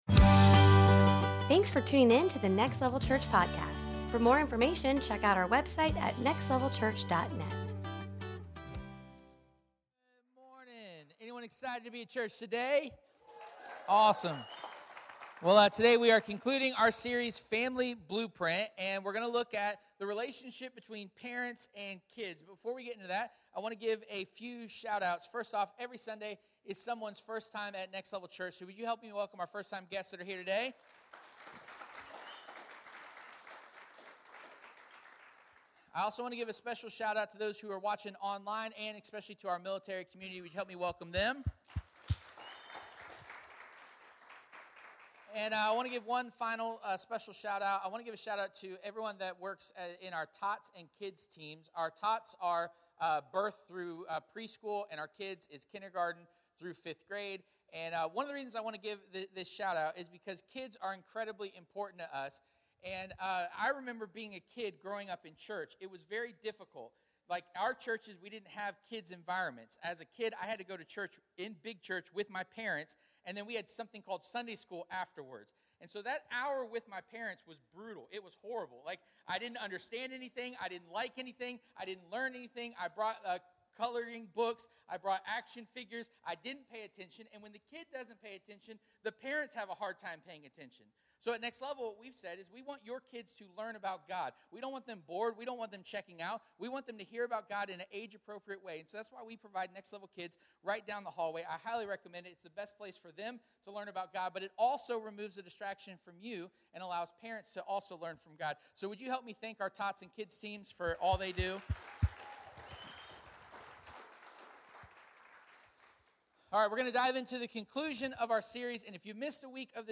Family Blueprint 2023 Service Type: Sunday Morning Topics